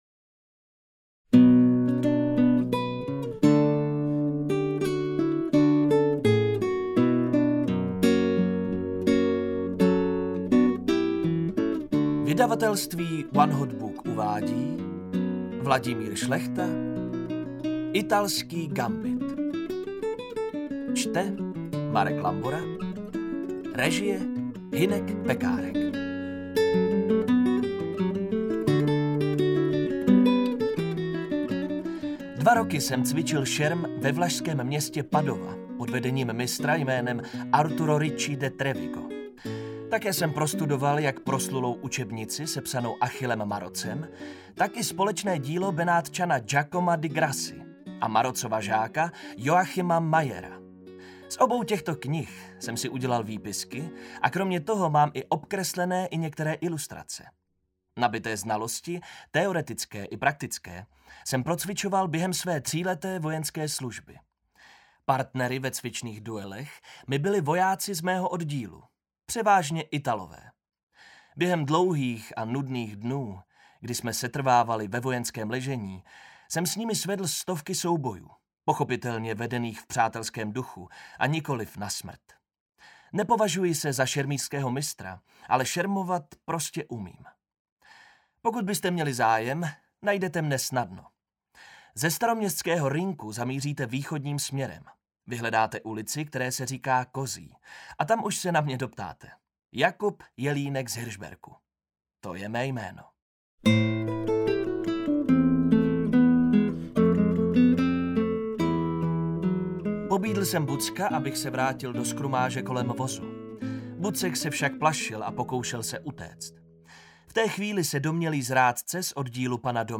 Italský gambit audiokniha
Ukázka z knihy
• InterpretMarek Lambora
italsky-gambit-audiokniha